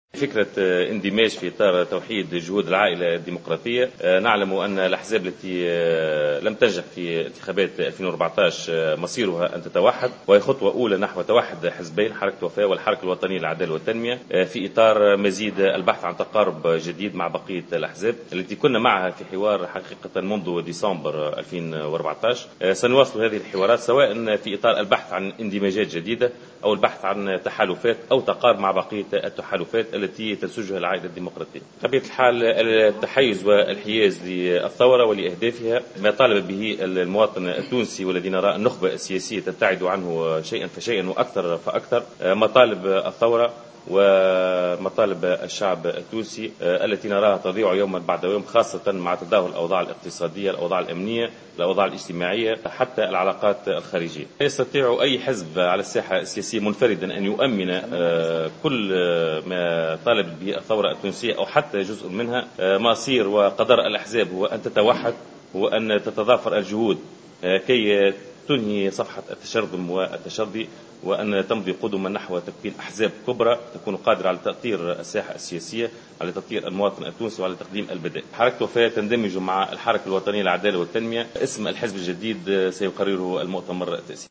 وقال بادي في تصريحات لـ"جوهرة أف أم" إن هذا الاندماج تم في إطار جهود العائلة الديمقراطية خاصة بالنسبة للأحزاب التي لم تنجح في انتخابات 2014 ليصبح مصيرها الاتحاد والتّقارب، بحسب تعبيره وأكد أن محاولات الاندماج انطلقت منذ ديسمبر 2014 وستشمل تحالفات أخرى مع أحزاب متقاربة تسعى لتحقيق أهداف الثورة التونسية.